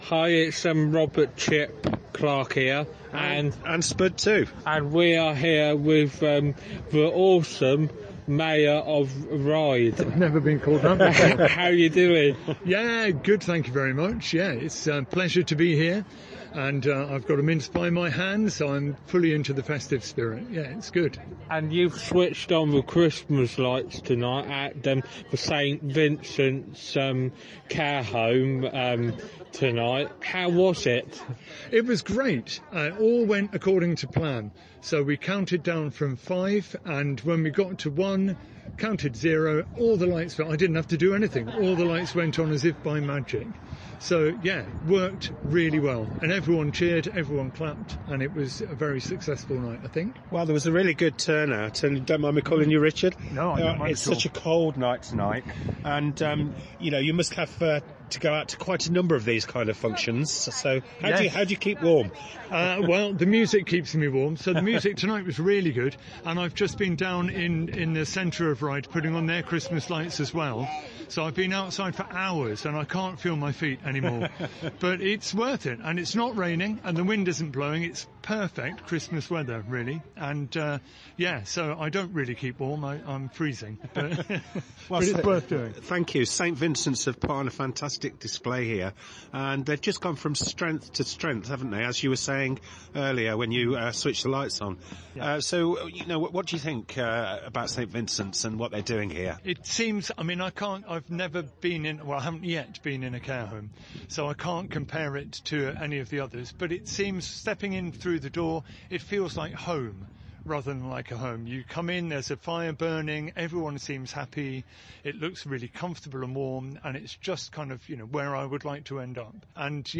Richard interview (Mayor of Ryde) interview (St Vincents Christmas light switch on 2023)
St Vincents residential home Christmas light switch on 2023